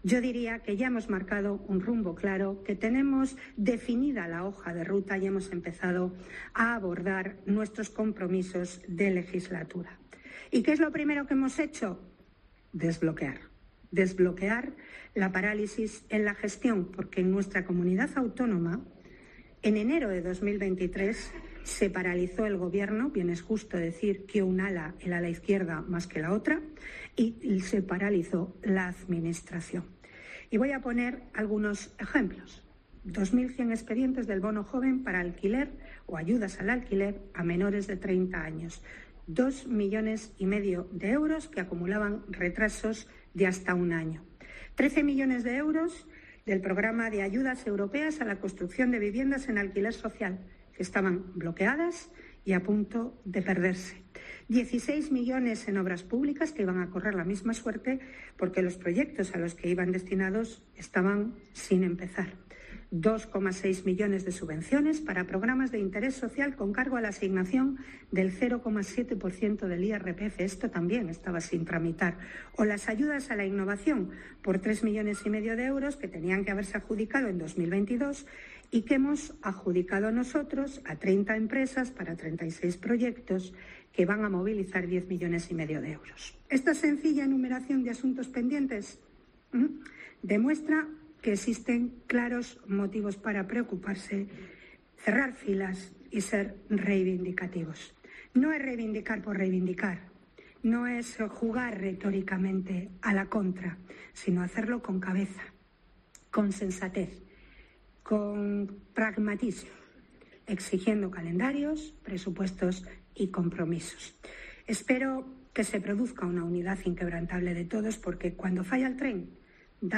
“En enero de 2023 se paralizó el Gobierno y lo primero que hemos hecho en estos 100 días ha sido ponerlo en marcha” ha dicho la presidenta del ejecutivo regional María José Sáenz de Buruaga durante una conferencia organizada por el Diario Montañés.